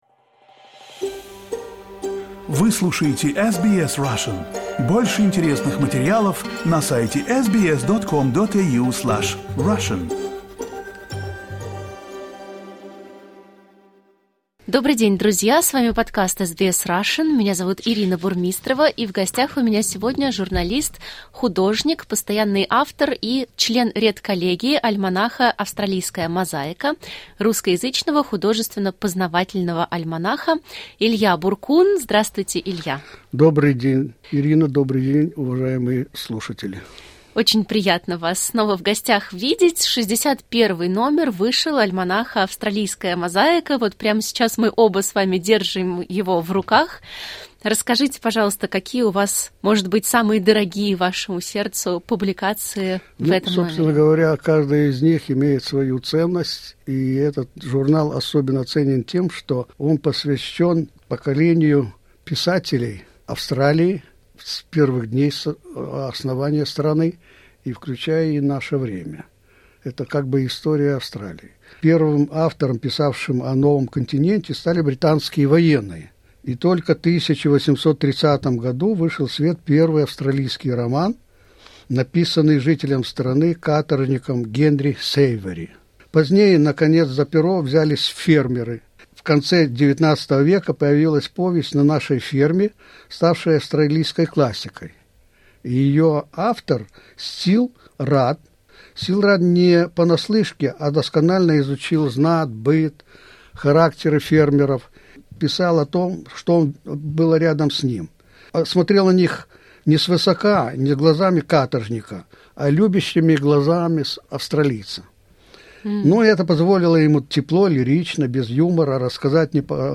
а также читает стихи Бахыта Кенжеева и других поэтов.